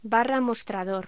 Locución: Barra mostrador